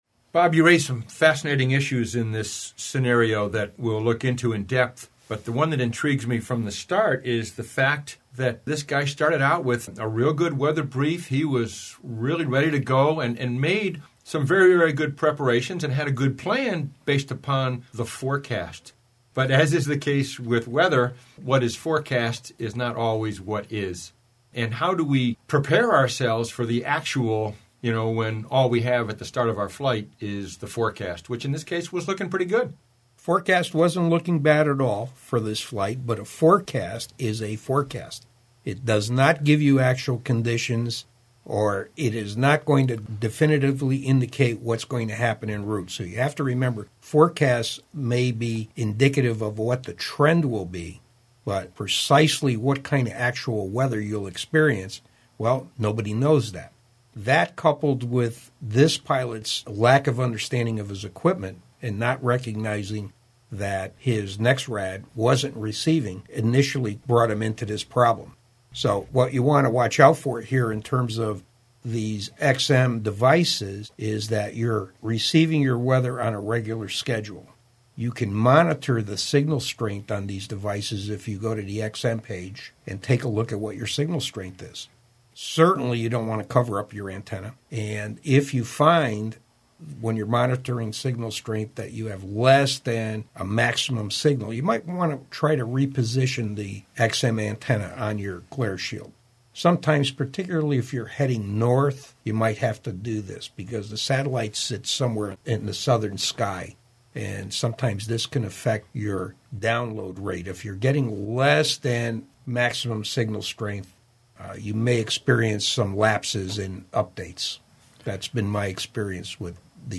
IFRM31_roundtable.mp3